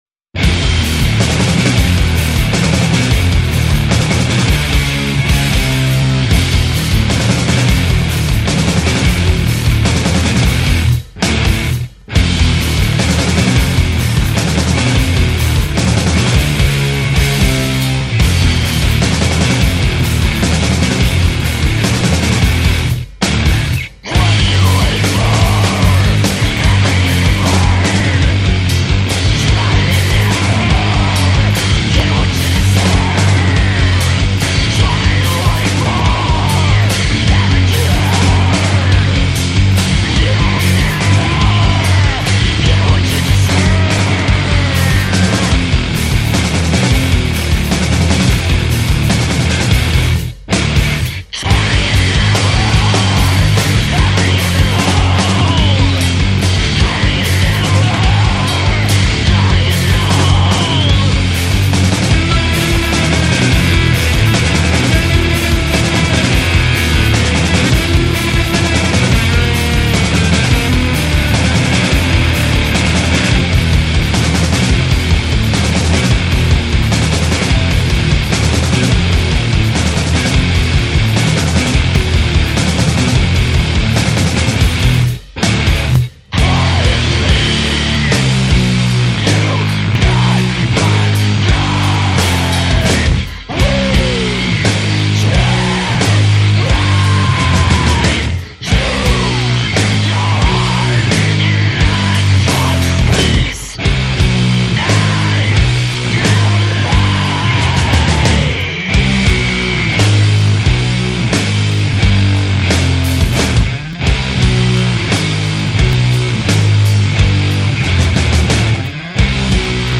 prog-sludge crushers